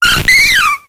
infinitefusion-e18 / Audio / SE / Cries / BELLOSSOM.ogg